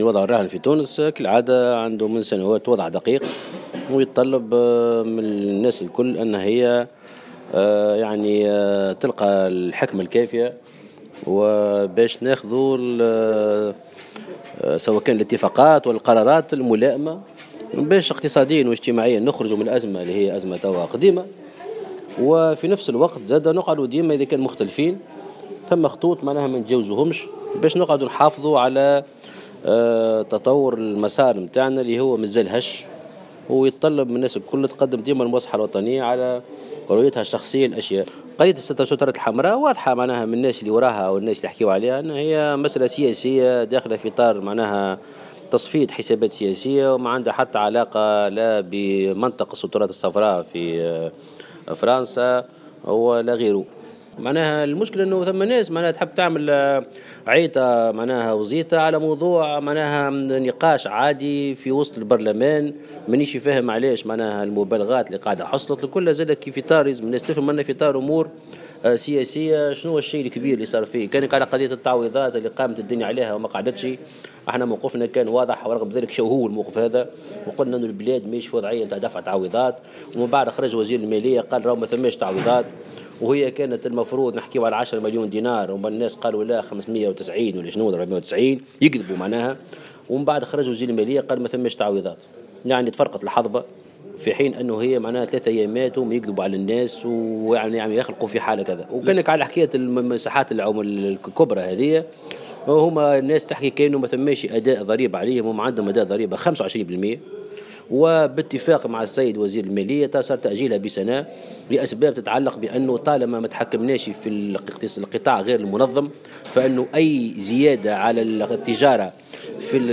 كما ابرز في تصريح لمراسلة "الجوهرة اف أم" على هامش ندوة للحزب بنابل تحت عنوان محيط اقتصادي جديد من اجل جيل جديد، ان طرح مسالة التعويضات كانت محض افتراءات وغير صحيحة خاصة بعد ان اكد وزير المالية انه لا توجد تعويضات.